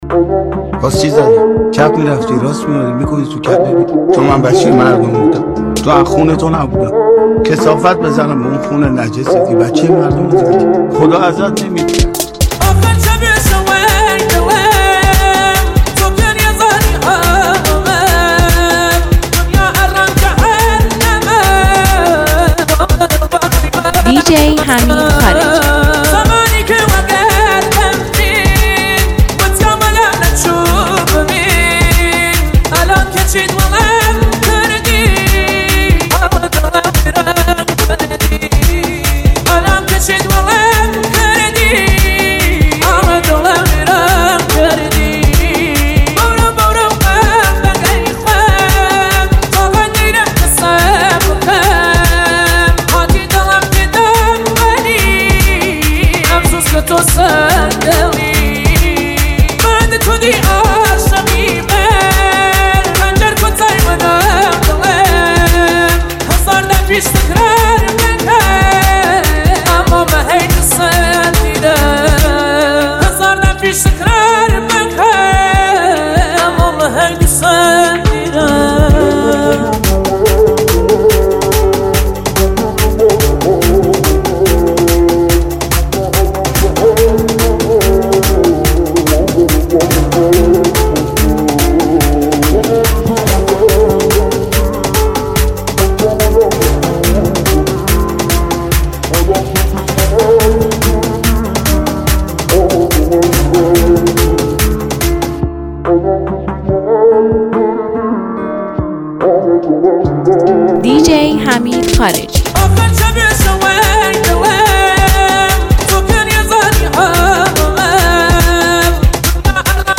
دانلود ریمیکس این آهنگ